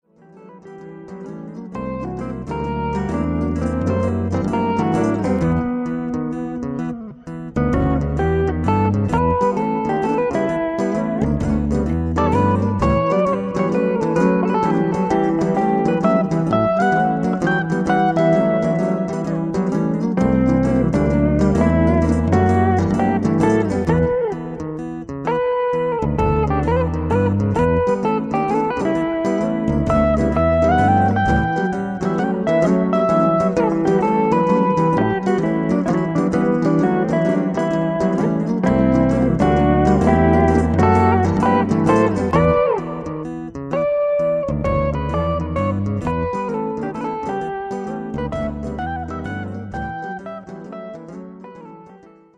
Looped layered rythms as a background for a solo.